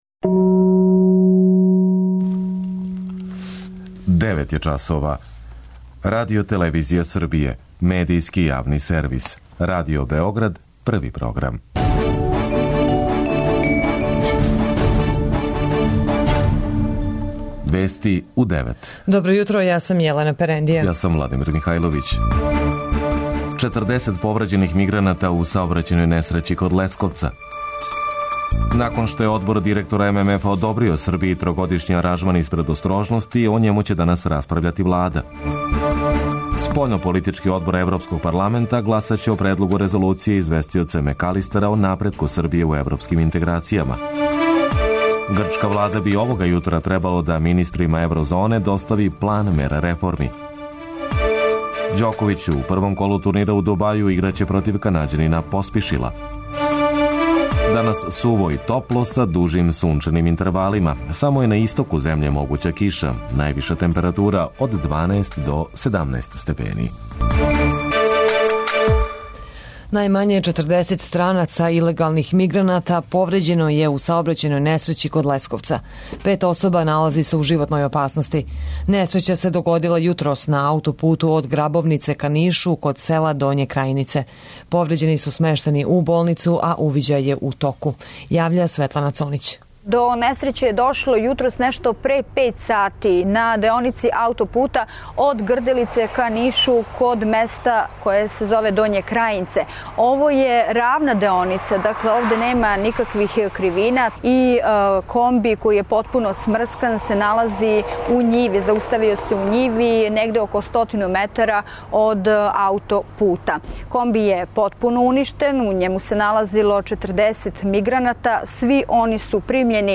Највиша температура од 12 до 17 степени. преузми : 10.05 MB Вести у 9 Autor: разни аутори Преглед најважнијиx информација из земље из света.